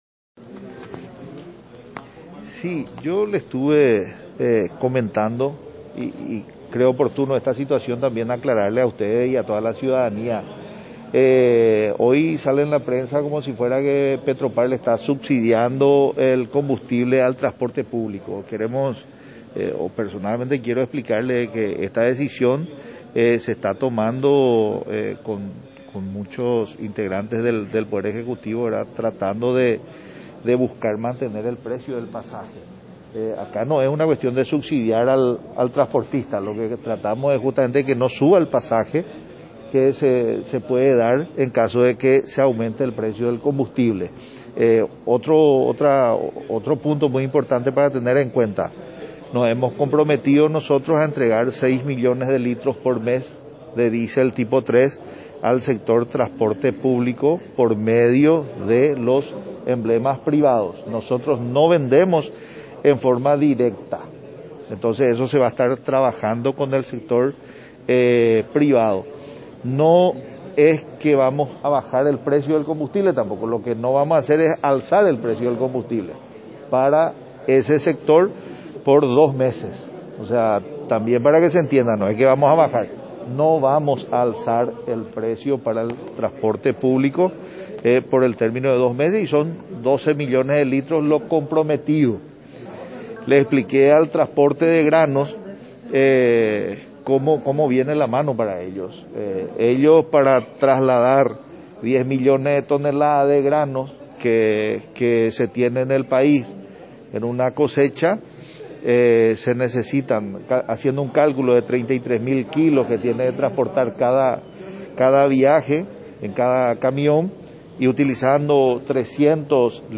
24-CONFERENCIA-DE-DENIS-LICHI.mp3